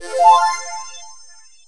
SFX完成关卡音效下载
SFX音效